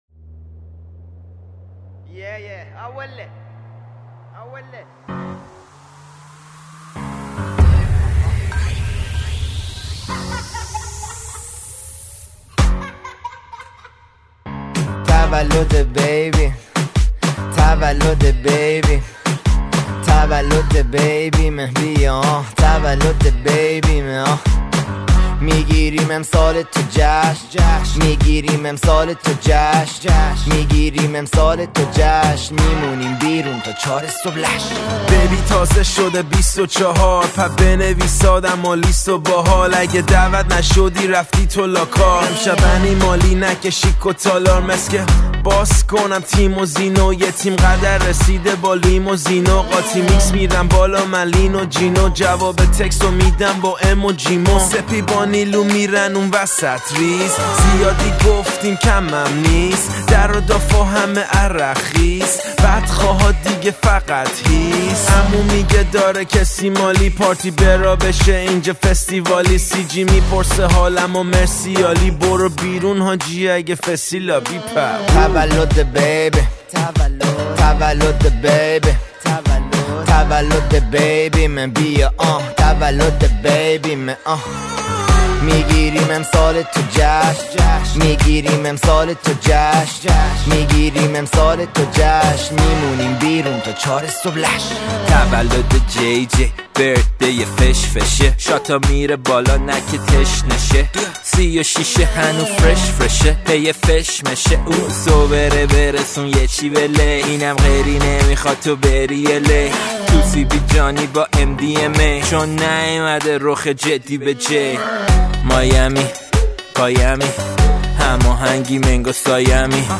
hiphop & rap